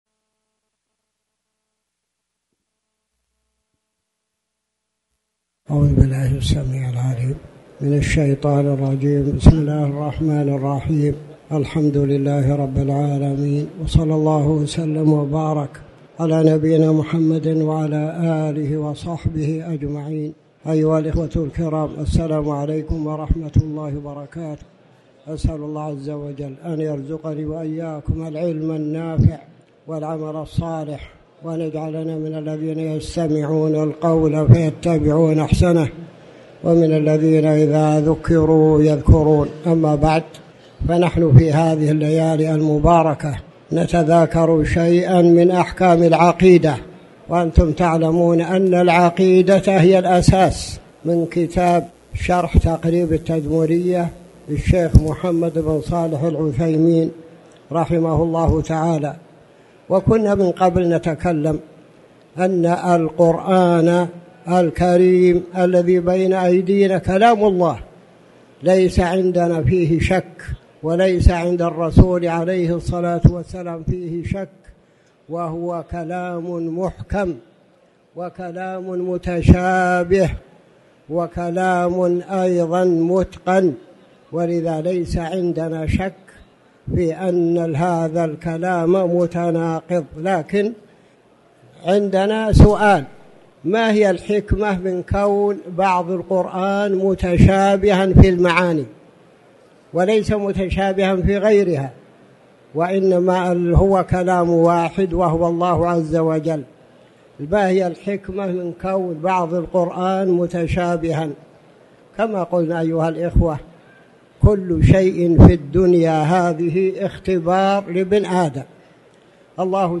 تاريخ النشر ١٠ ربيع الثاني ١٤٤٠ هـ المكان: المسجد الحرام الشيخ